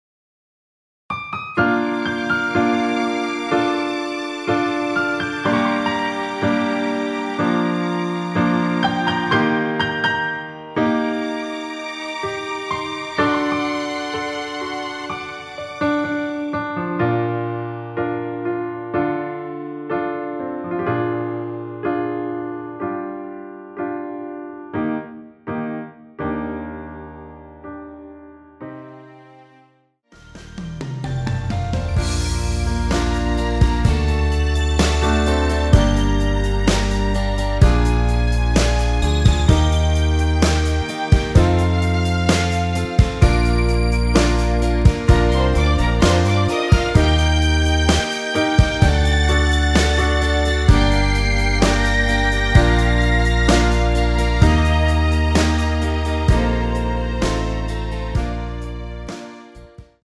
원키에서(+5)올린 여성분이 부르실수 있는 키의 MR입니다.
Bb
앞부분30초, 뒷부분30초씩 편집해서 올려 드리고 있습니다.